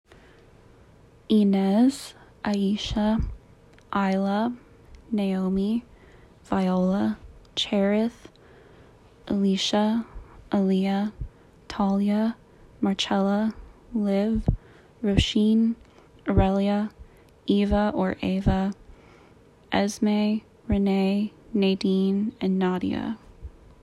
I felt like recording me saying them would be easier than writing it out since those often get misconstrued :sweat_smile: